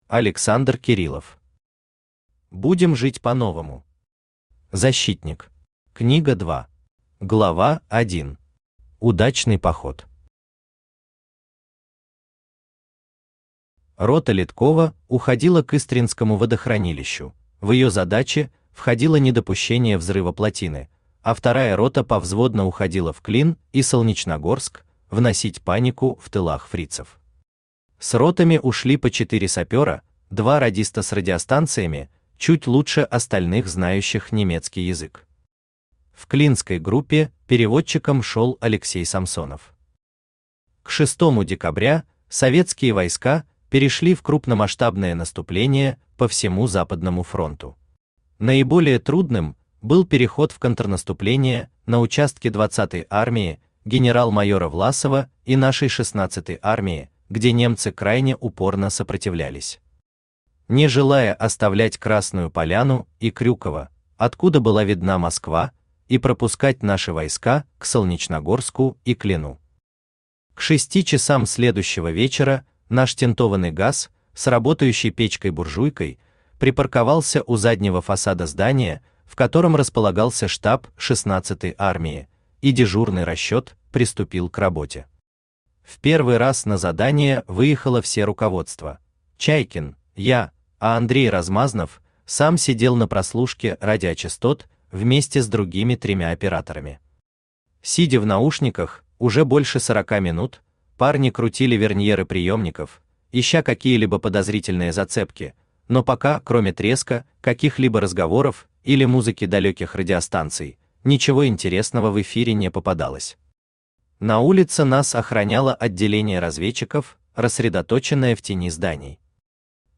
Аудиокнига Будем жить по-новому! Защитник. Книга 2 | Библиотека аудиокниг
Книга 2 Автор Александр Леонидович Кириллов Читает аудиокнигу Авточтец ЛитРес.